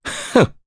Mitra-Vox_Happy1_jp.wav